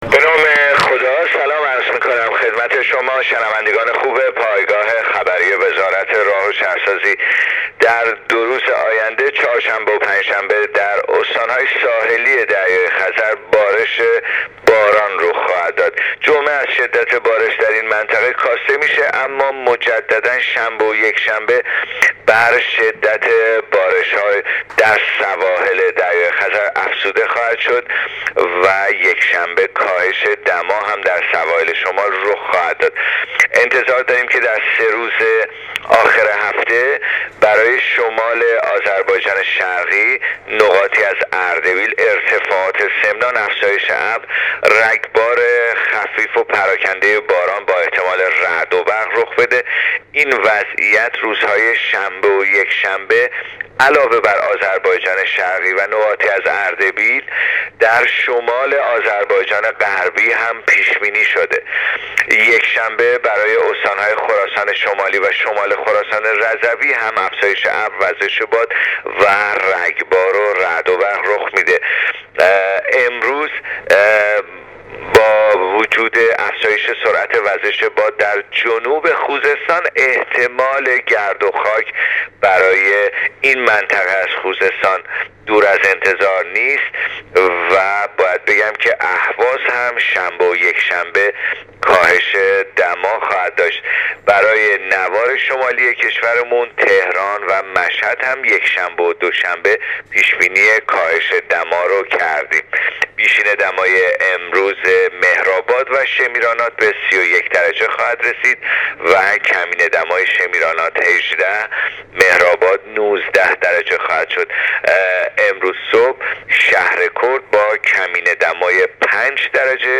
گزارش رادیو اینترنتی وزارت راه و شهرسازی از آخرین وضعیت آب‌وهوای ۱۳ شهریور ۹۸/ دو روز آینده نوار ساحلی خزر بارانی می‌شود/ وقوع گردوخاک در جنوب خوزستان محتمل است